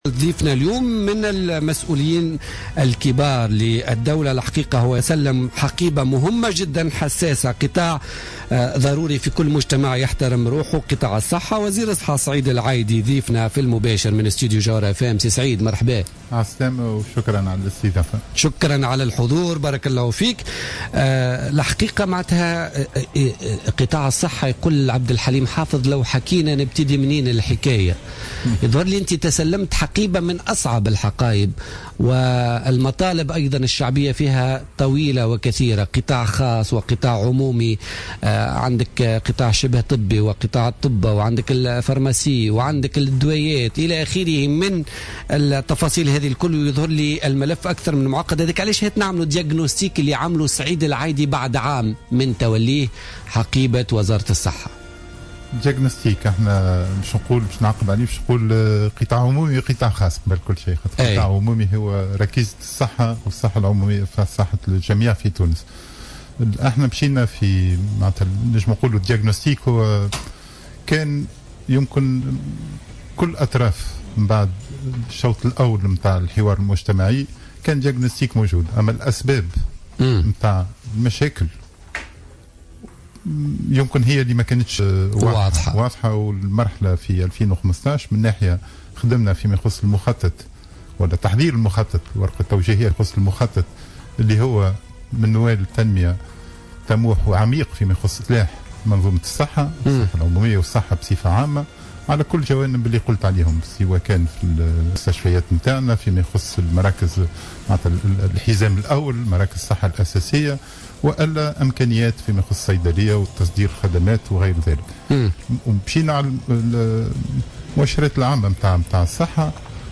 قال وزير الصحة سعيد العايدي ضيف برنامج بوليتكا لليوم الخميس 10 ديسمبر 2015 إنه تم تخصيص 48 مليون دينار من ميزانية وزارة الصحة لسنة 2016 لتوفير الأدوية للمصابين بأمراض مزمنة على غرار مرض السكري وضغط الدم باعتبارها أحد أهم الإصلاحات المبرمجة في إطار الخطة الإستراتيجية لإصلاح المنظومة الصحية.